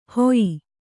♪ hoyi